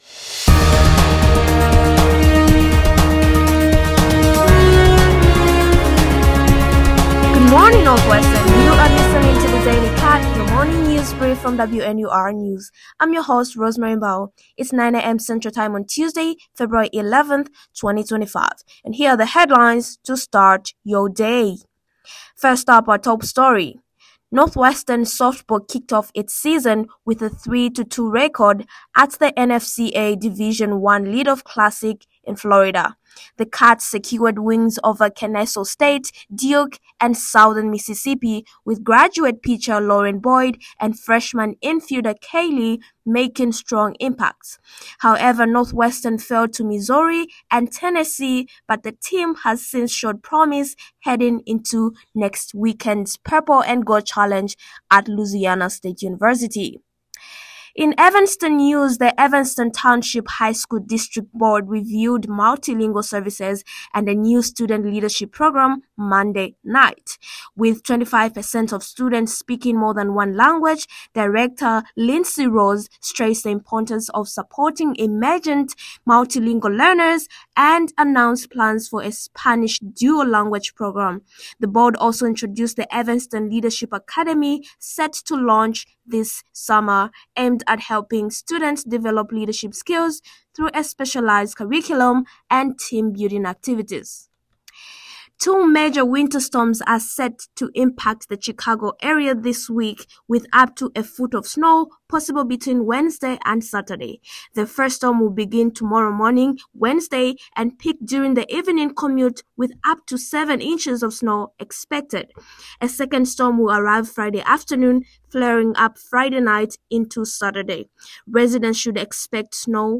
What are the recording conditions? February 11, 2025: Super Bowl, Northwestern sports, Evanston School District Board, Midwest weather, Paris AI Summit. WNUR News broadcasts live at 6 pm CST on Mondays, Wednesdays, and Fridays on WNUR 89.3 FM.